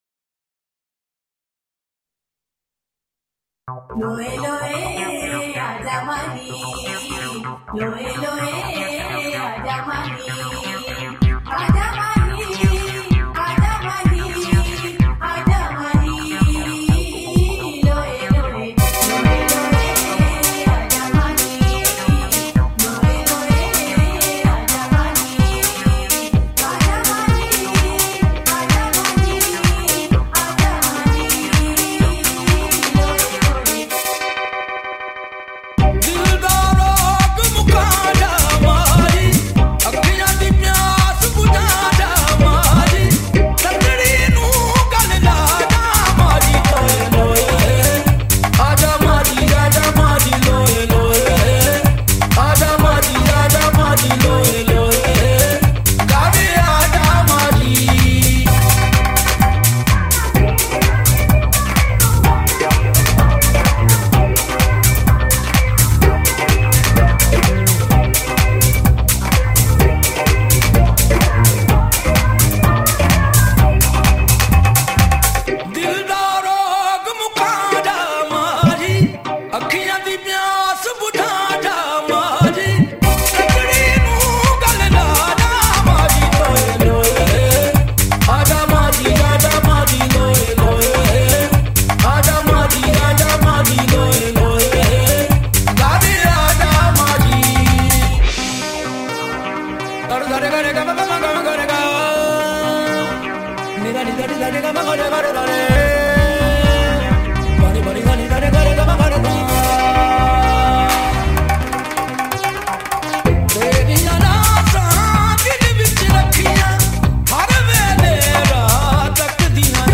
Sufi Collection
Punjabi Qawwali